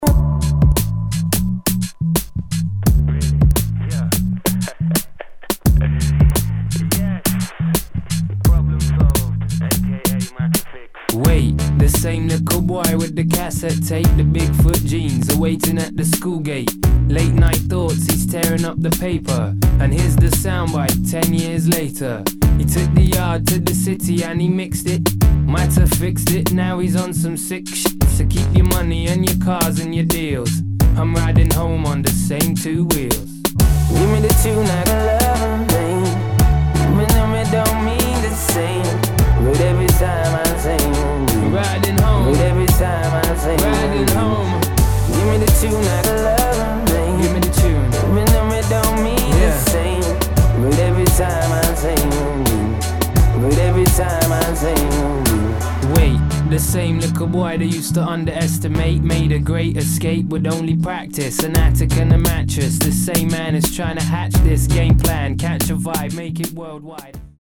[ BASS ]